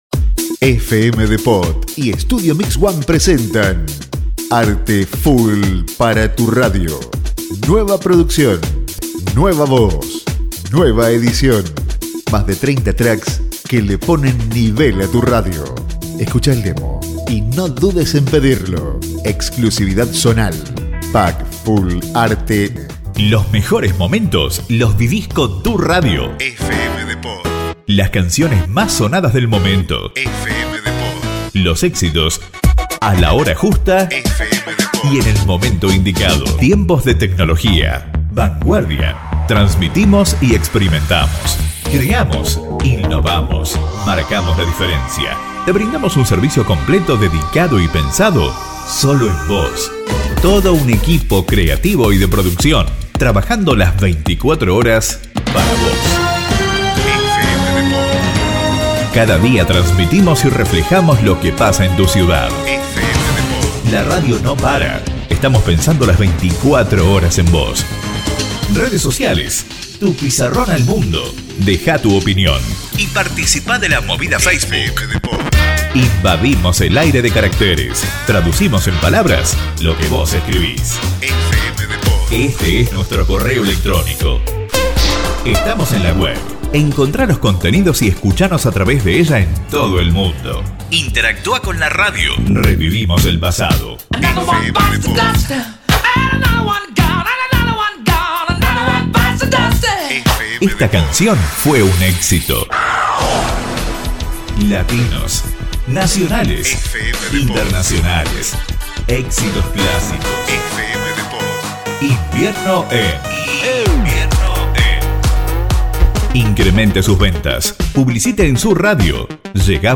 NUEVA VOZ + NUEVAS BASES MUSICALES
TODOS EDITADOS, PERSONALIZADOS Y MUSICALIZADOS CON FX DE ALTA CALIDAD...
UN NUEVO AIRE PARA DISTINGUIRSE DE LA COMPETENCIA CON FRASES CREATIVAS Y EXCLUSIVAS Y UN FORMATO LLENO DE DINAMISMO...